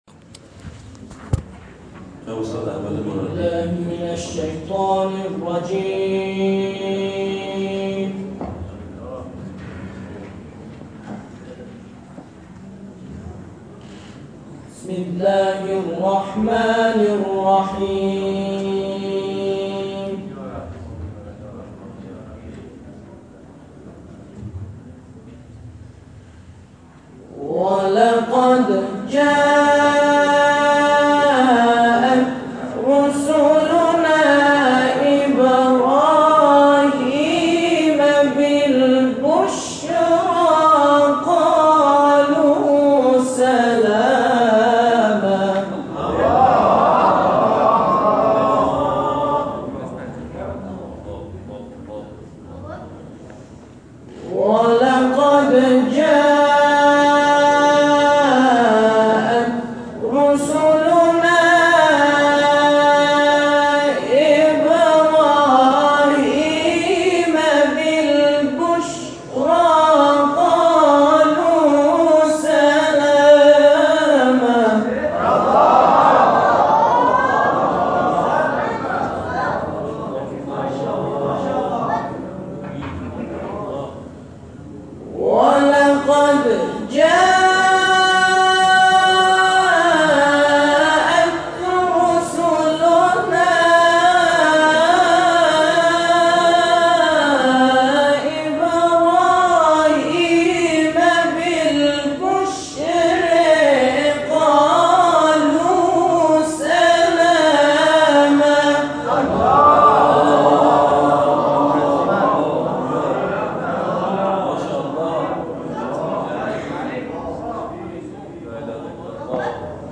گروه جلسات و محافل: گروه همخوانی سبیل الرشاد با حضور در حسینیه صاحب‌الزمانی(عج) به جمعخوانی آیاتی از سوره مبارکه هود به سبک استاد رفعت پرداختند.